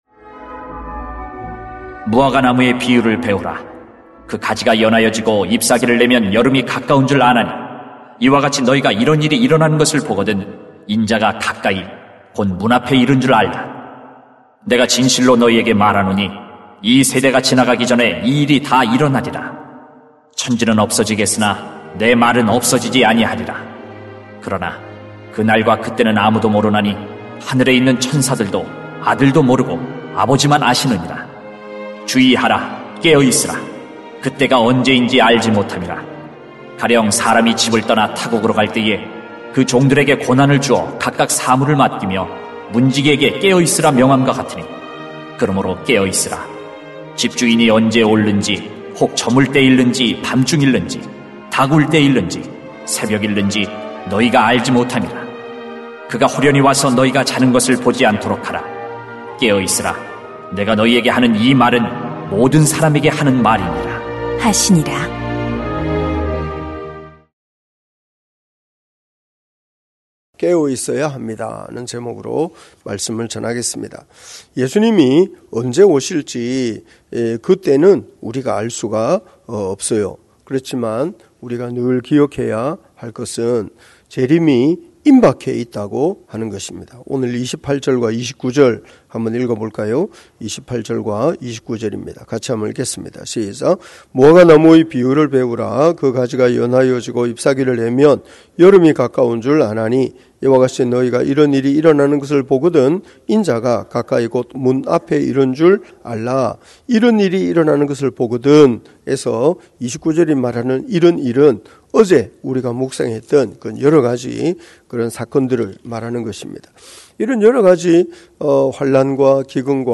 [막 13:28-37] 깨어 있어야 합니다 > 새벽기도회 | 전주제자교회